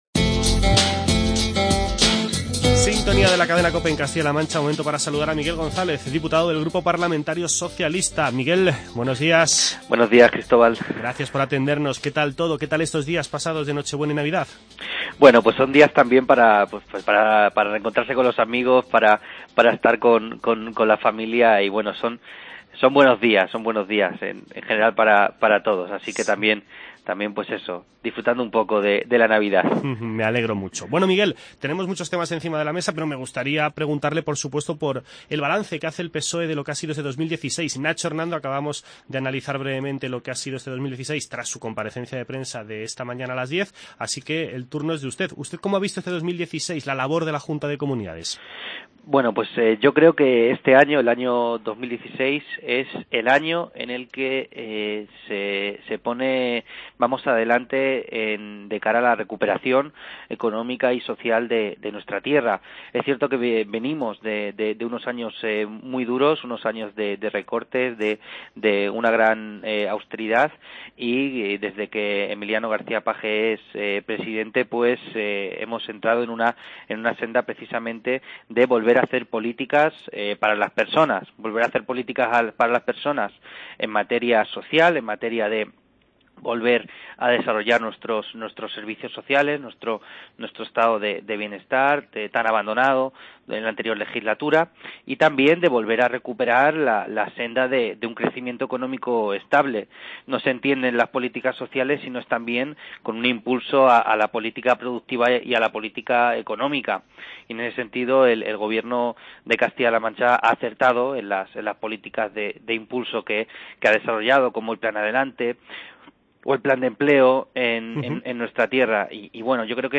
Entrevista con Miguel González, diputado regional del Partido Socialista.